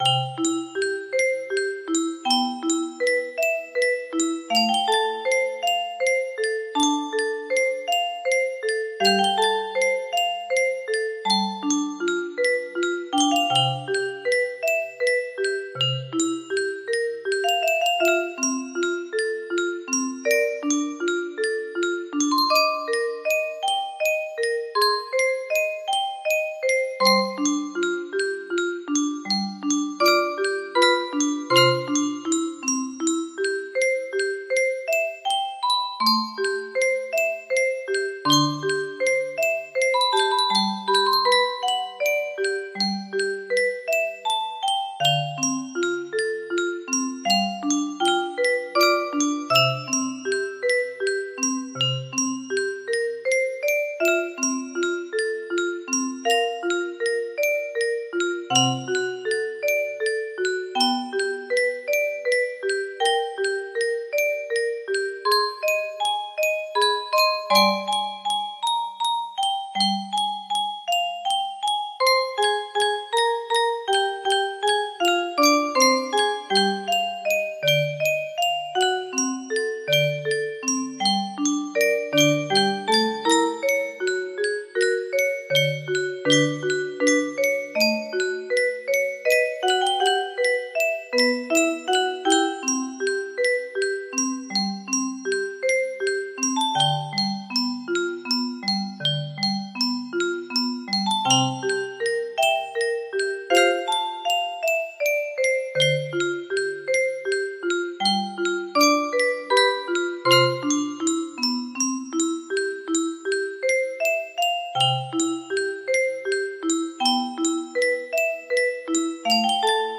A clean and expressive arrangement
– Faithful melodic phrasing with simplified harmonic support